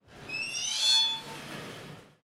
Звук ворот
Слышите тот скрип, когда ворота скользят по направляющим?
otkritie_vorota_qph.mp3